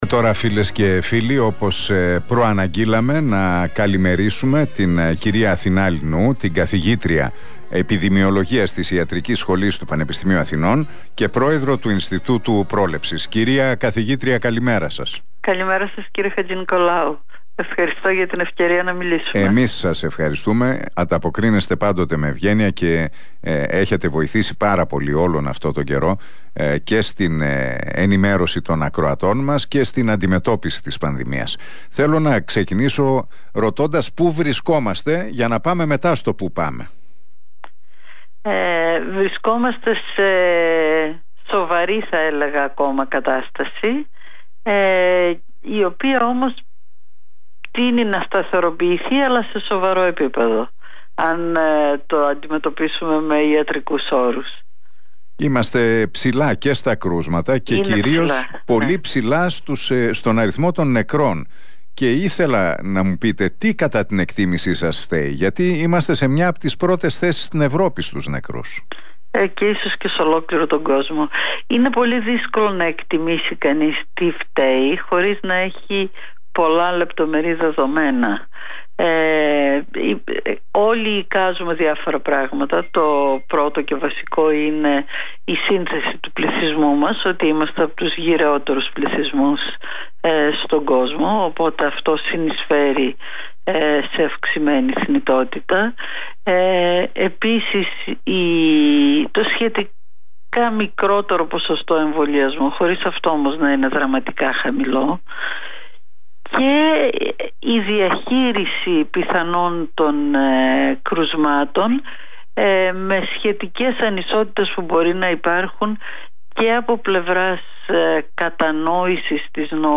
Η Αθηνά Λινού, καθηγήτρια Επιδημιολογίας στην Ιατρική Σχολή του Πανεπιστημίου Αθηνών και πρόεδρος του Ινστιτούτου Prolepsis, μίλησε στην εκπομπή του Νίκου Χατζηνικολάου στον Realfm 97,8 για την πορεία της πανδημίας.